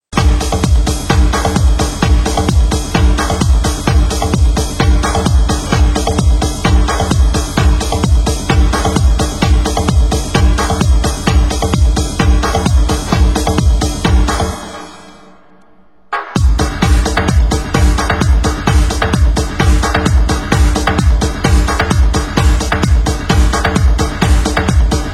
Genre: Break Beat